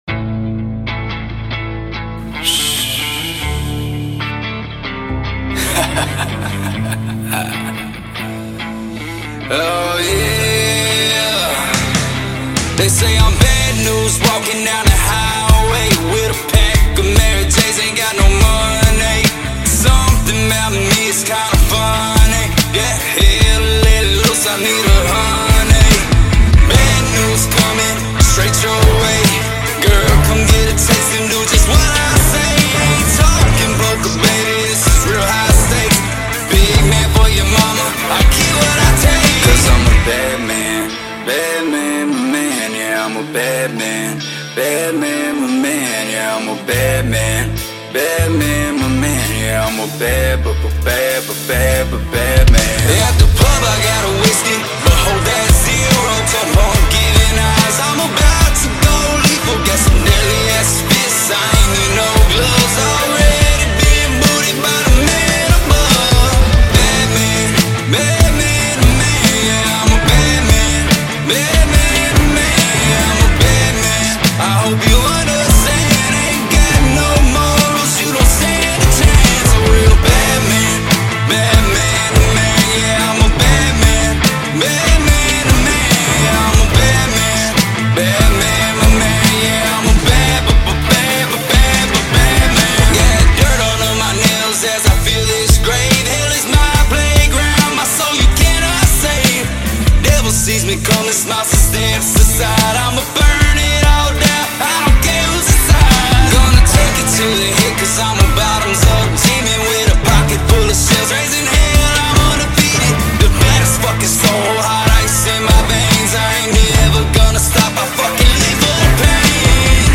American Country Hip-Hop Rocker